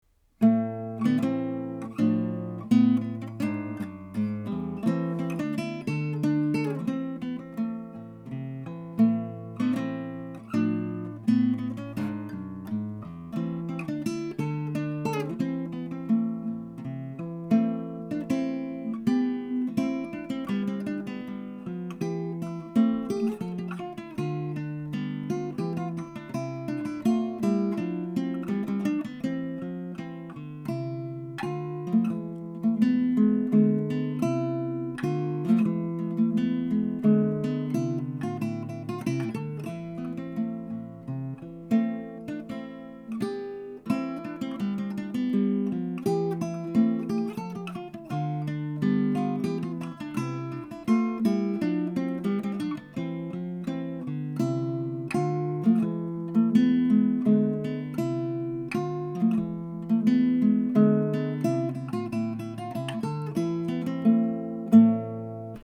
Ля-минор